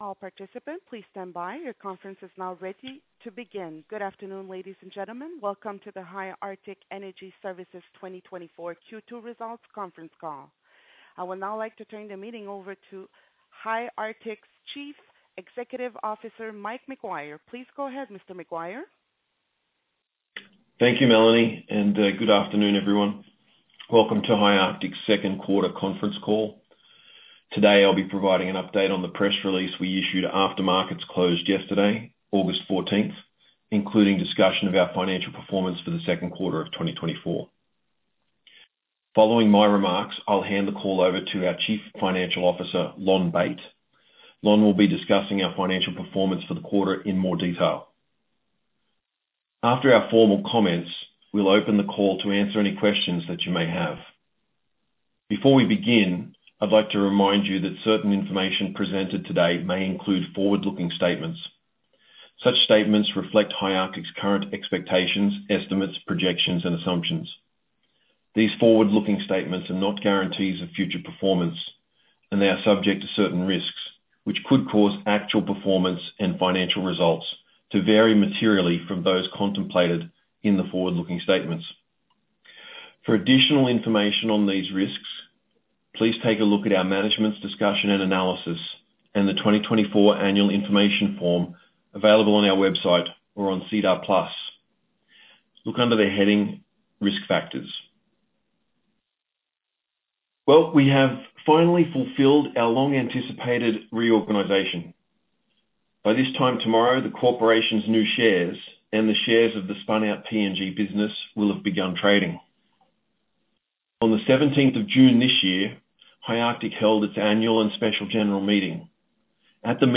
Conference Call Recordings